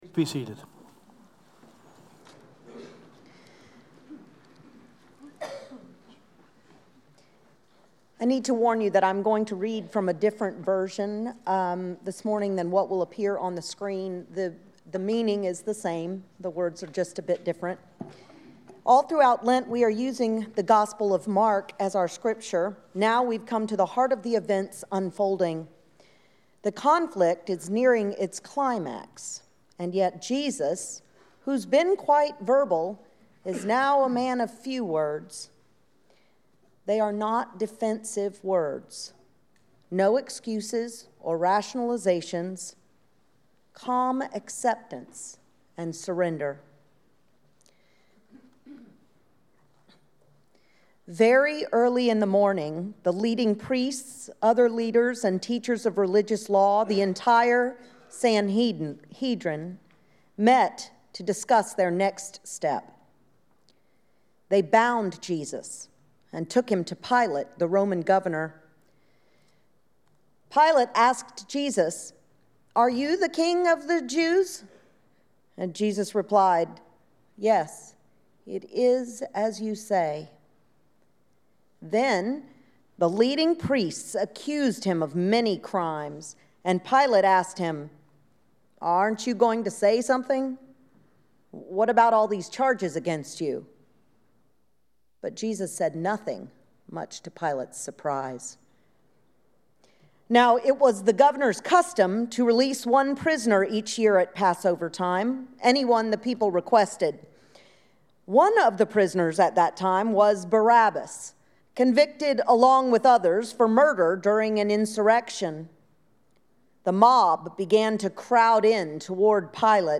Sermon: Blame and Choice.